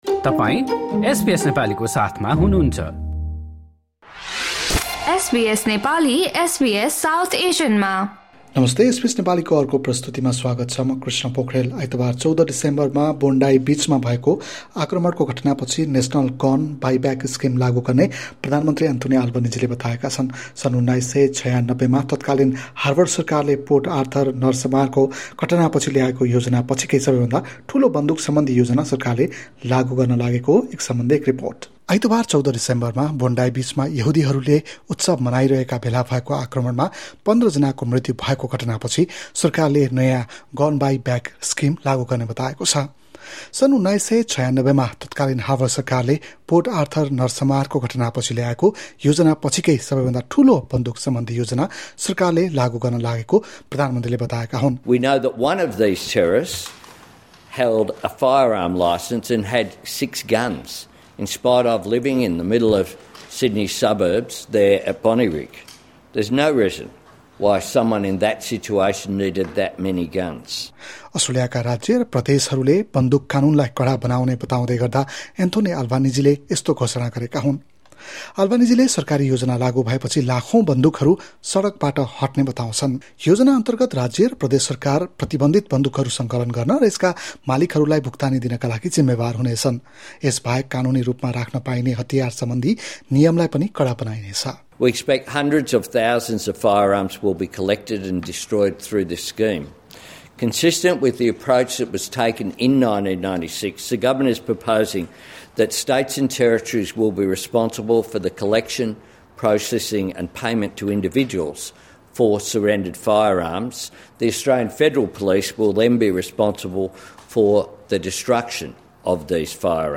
सन् १९९६ मा तत्कालीन सरकारले पोर्ट आर्थर नरसंहारको घटना पछि ल्याएको योजना पछि कै सबैभन्दा ठुलो बन्दुक नियन्त्रण सम्बन्धी योजना सरकारले लागू गर्न लागेको हो। एक रिपोर्ट।